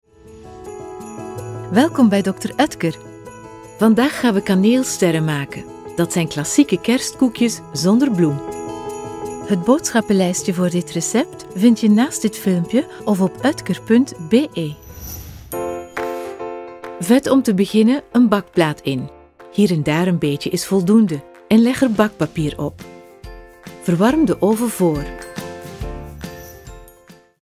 Sprechprobe: Industrie (Muttersprache):
TV-Interpreter Professional voice talent Flemish and European English
Dr Oetker NL für Belgien (Flämisch)  - Copy.mp3